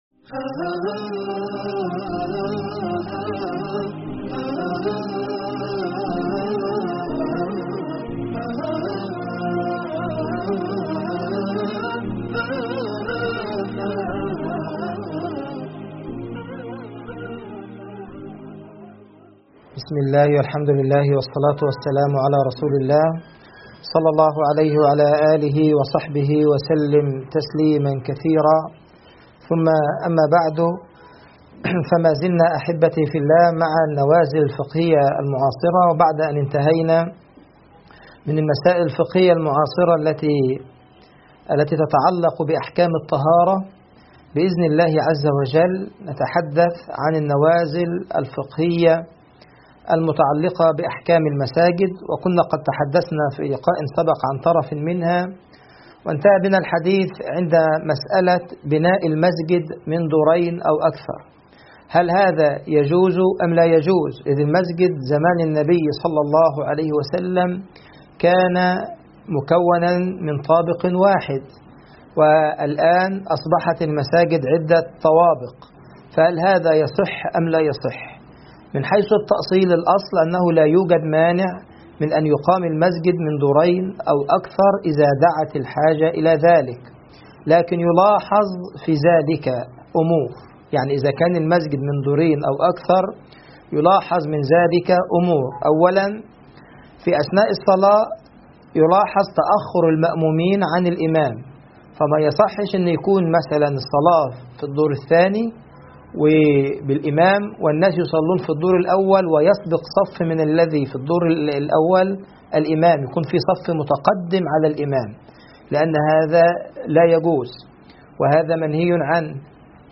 أحكام المساجد المحاضرة الثانية